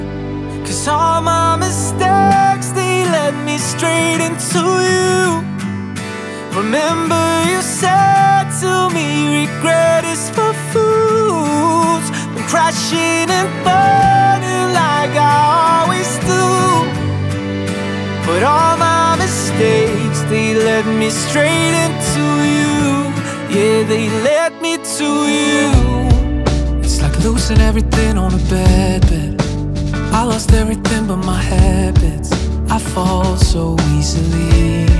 Жанр: Музыка из фильмов / Саундтреки